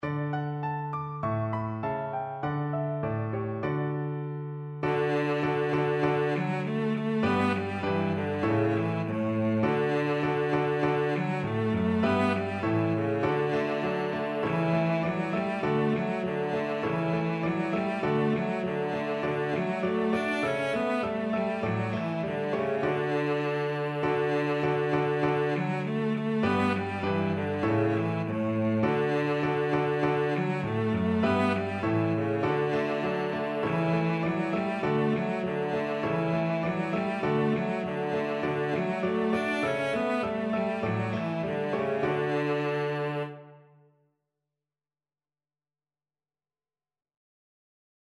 2/2 (View more 2/2 Music)
Two in a bar =c.100